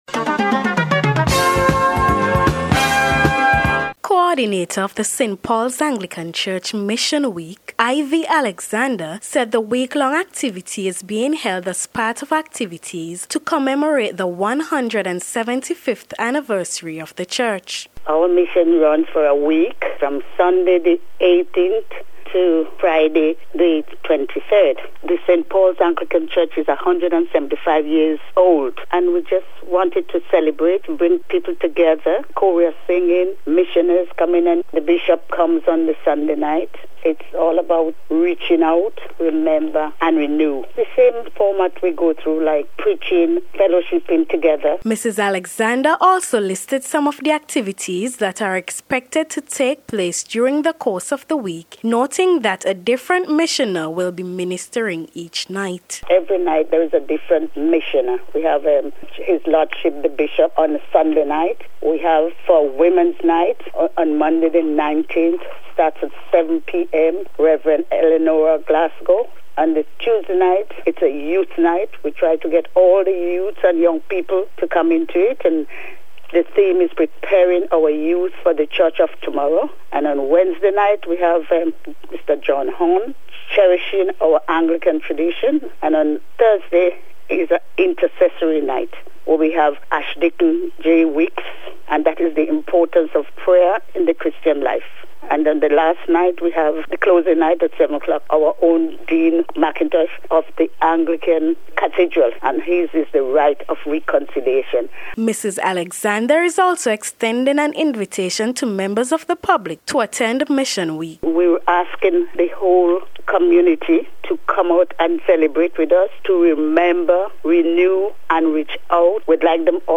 ST-PAULS-MISSION-WEEK-REPORT-.mp3